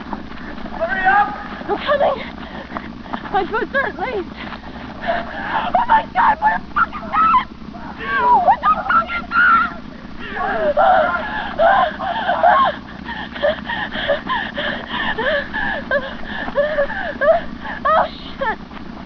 In one of the final nights out in the woods, They are chased out of thier tent by the screams and morphing of childrens voices, and the cries of a baby.